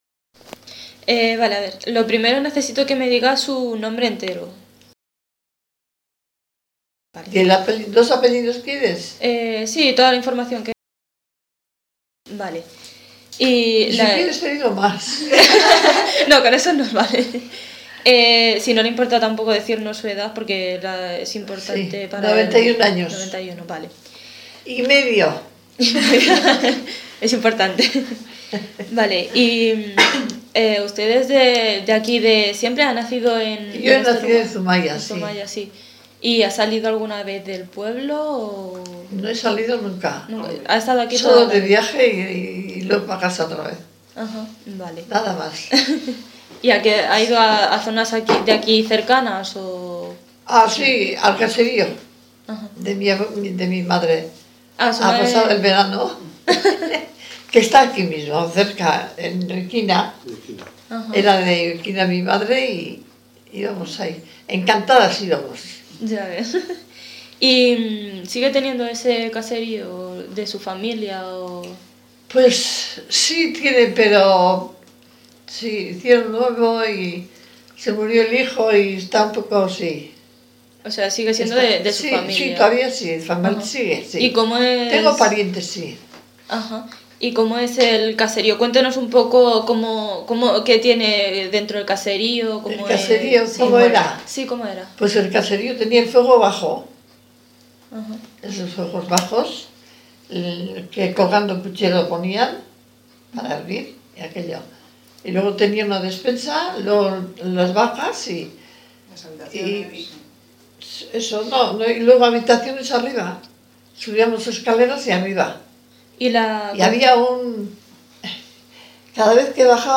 Encuesta